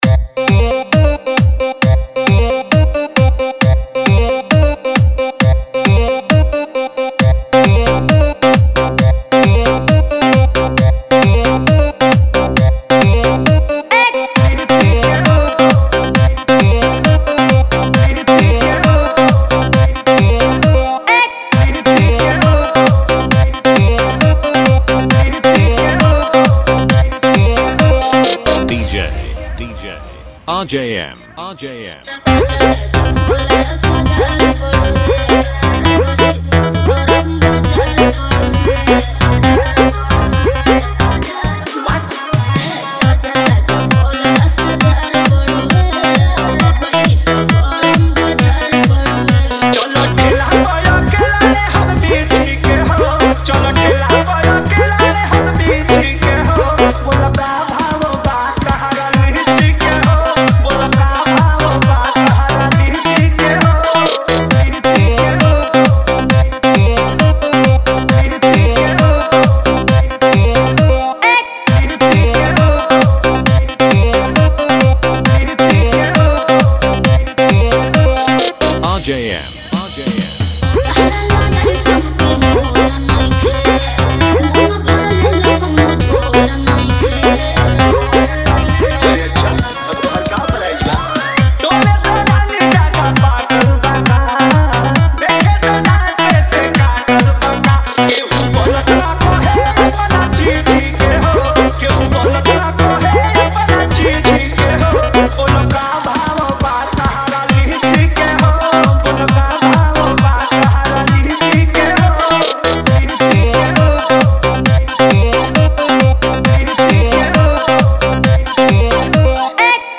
2025 Bhojpuri DJ Remix - Mp3 Songs
Bhojpuri Dj Songs